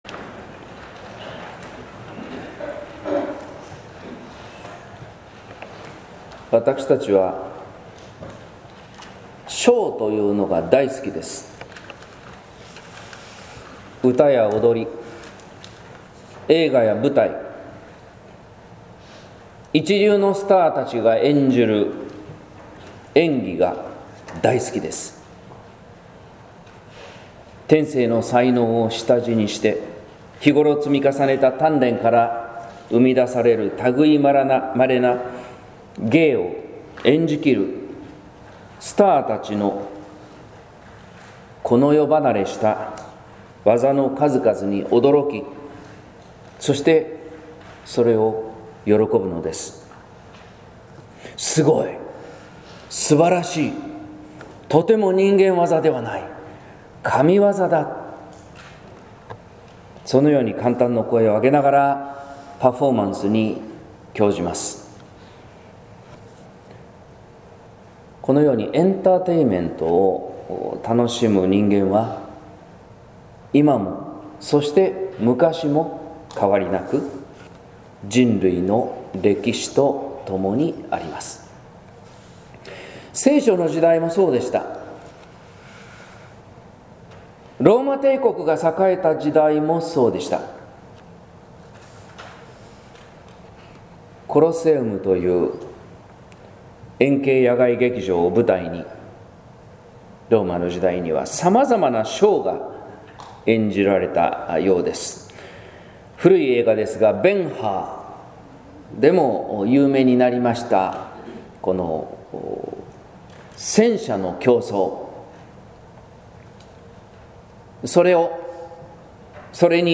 説教「隠れたる神」（音声版）